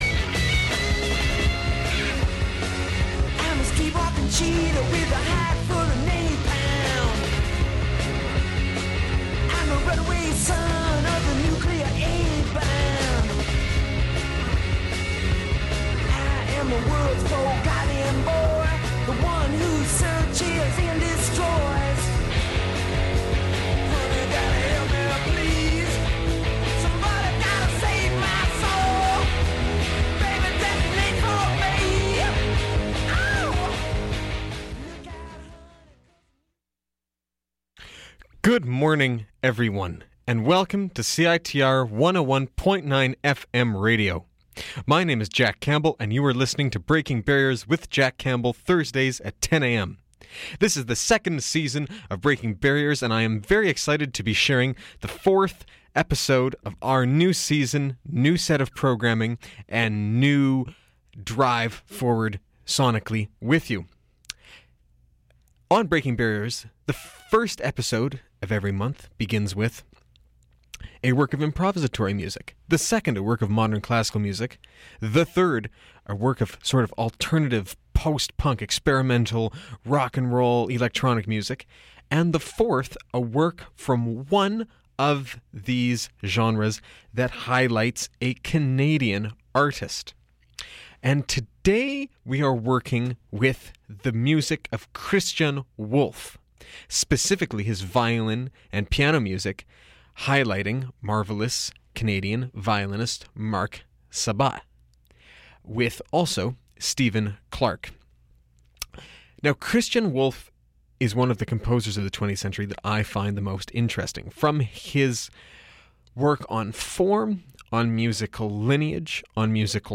Violin/Piano